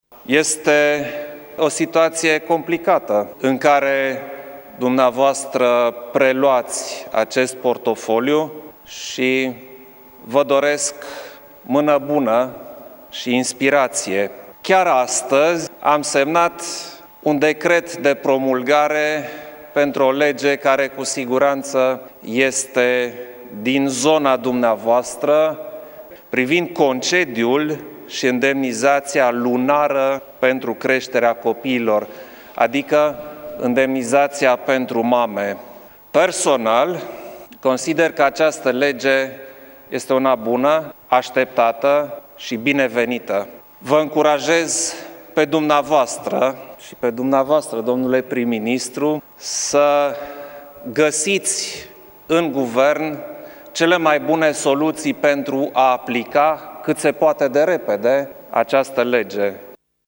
Preşedintele Klaus Iohannis a anuțat în cadrul ceremoniei că a promulgat legea privind indemnizaţia pentru mame.